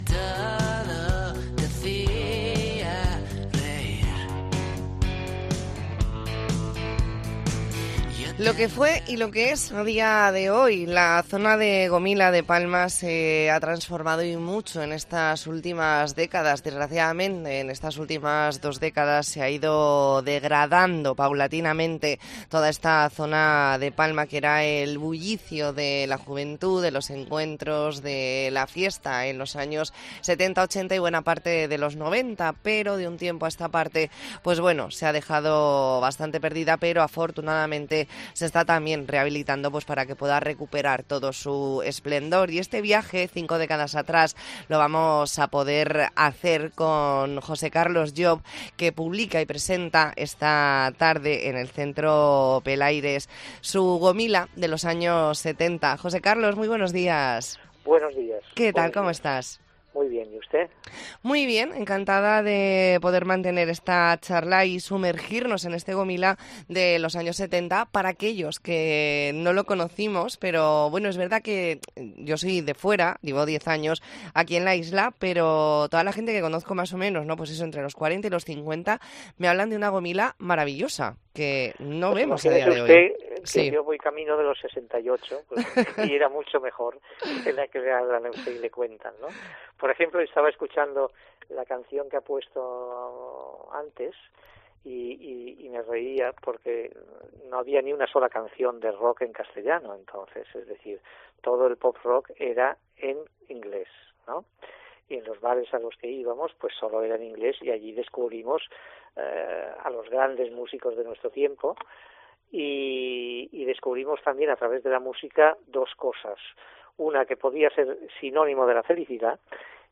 Redacción digital Madrid - Publicado el 14 dic 2023, 13:20 - Actualizado 14 dic 2023, 16:00 1 min lectura Descargar Facebook Twitter Whatsapp Telegram Enviar por email Copiar enlace Hablamos con el escritor José Carlos Llop . Entrevista en La Mañana en COPE Más Mallorca, jueves 14 de diciembre de 2023.